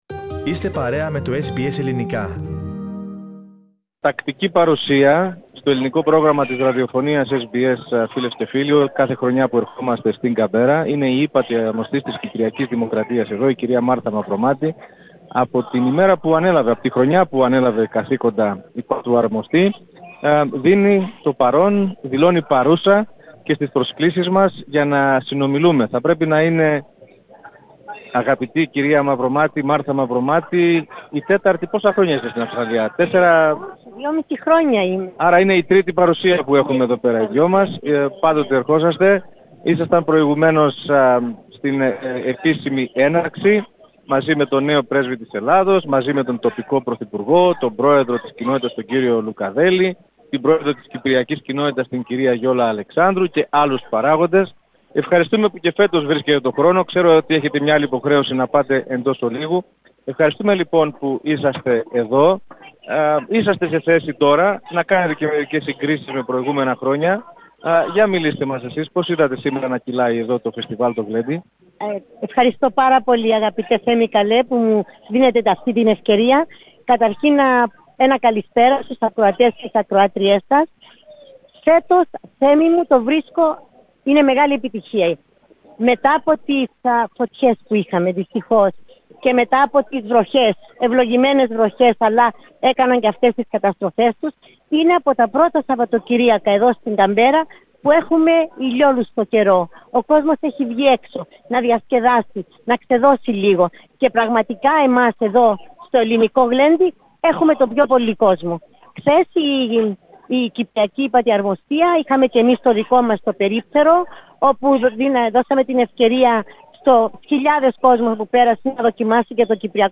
Στο Ελληνικό Γλέντι της πρωτεύουσας βρέθηκε για ακόμη μια χρονιά η ύπατη Αρμοστής της Κυπριακής Δημοκρατίας στην Καμπέρρα, Μάρθα Μαυρομάτη. Η κα Μαυρομάτη μίλησε στο Ελληνικό Πρόγραμμα της Ραδιοφωνίας SBS, τόσο για την άρτια όπως την χαρακτήρισε διοργάνωση, όσο και για τους νέους της ομογένειας.